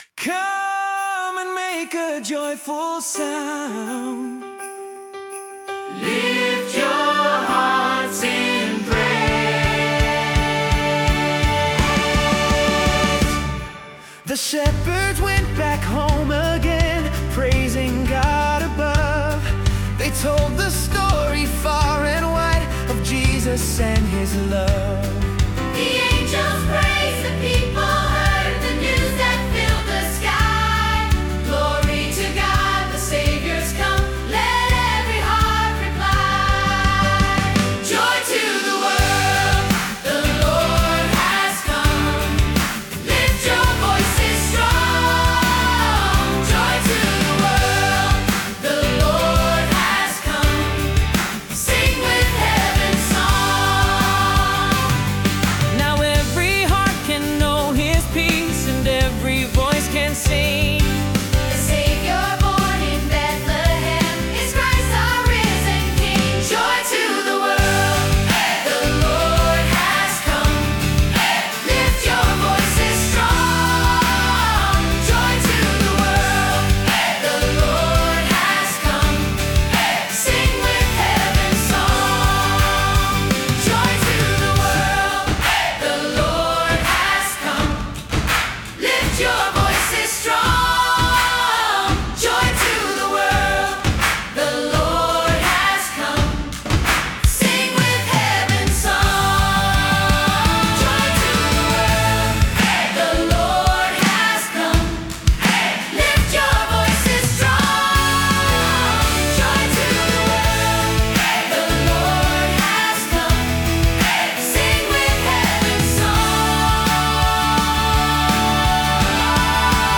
Joy to the World ! Sing along